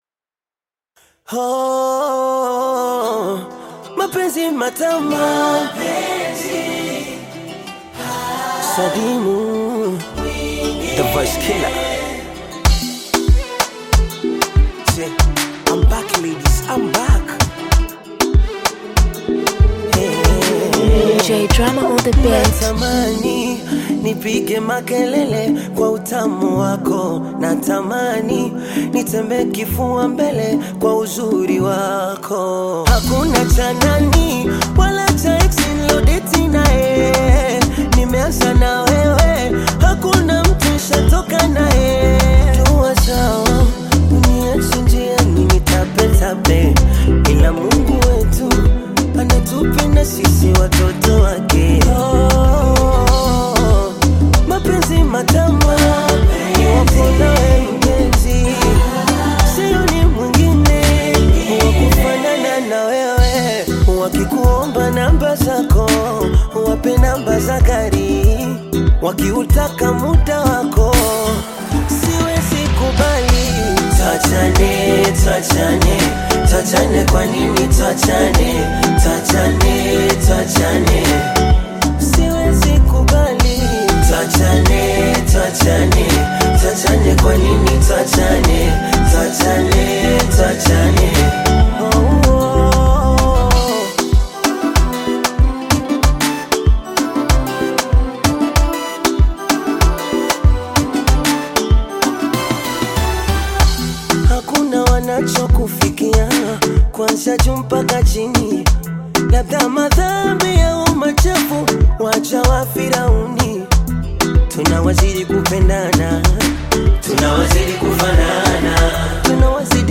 Tanzanian Bongo Flava artist, singer and songwriter
Bongo Flava